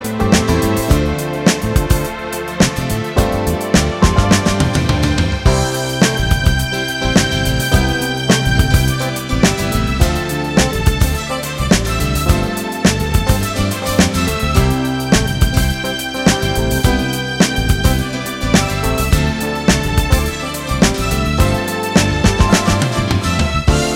No Guitars Or Backing Vocals Disco 3:14 Buy £1.50